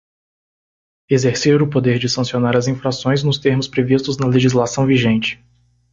Pronúnciase como (IPA)
/sɐ̃.si.oˈna(ʁ)/